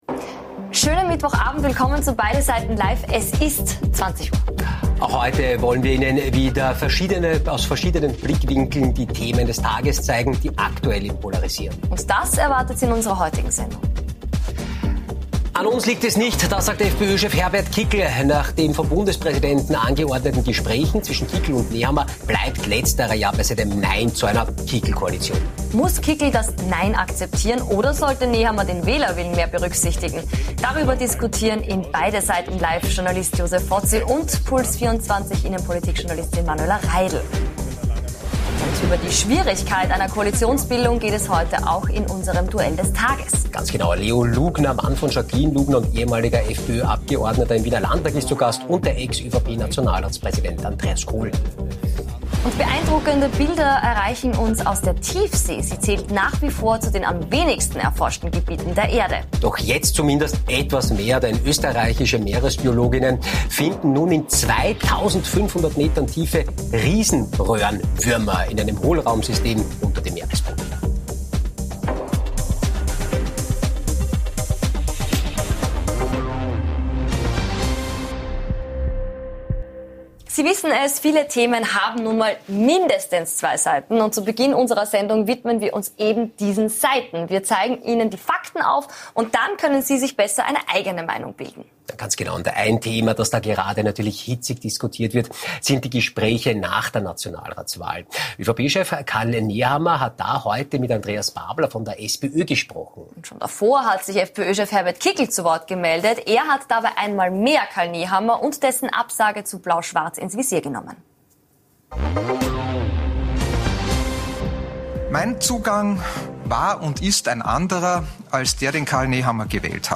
Wir lassen folgende Tagesnachrichten Revue passieren: Österreicherin entdeckt Tiefsee-Wurm ÖBB-Tickets werden teurer Schwarze Kugeln an Sydneys Stränden sorgen für Rätselraten China verschenkt Pandas Und nachgefragt haben wir heute bei gleich zwei Gästen - im großen Beide Seiten Live Duell mit der ehemaligen FPÖ-Gemeinderat in Wien Leo Lugner und dem ehemaligen Nationalratspräsidenten der ÖVP Andreas Khol.